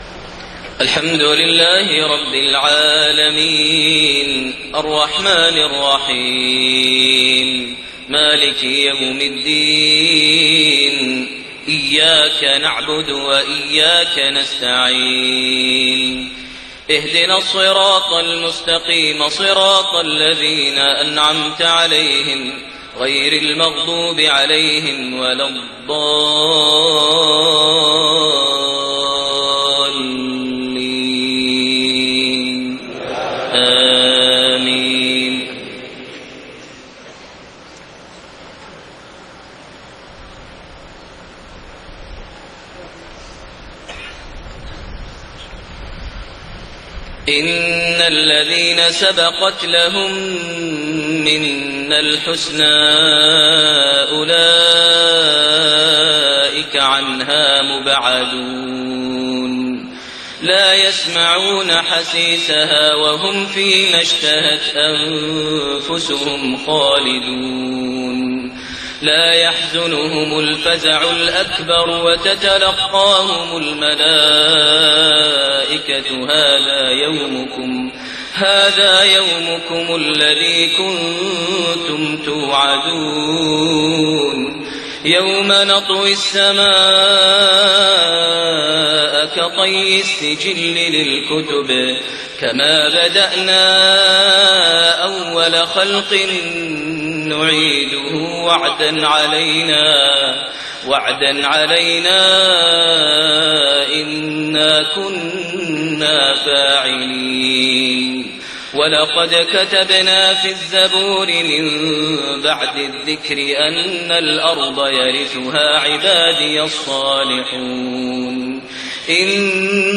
صلاة المغرب 5-4-1430 من سورة الأنبياء101-112 > 1430 هـ > الفروض - تلاوات ماهر المعيقلي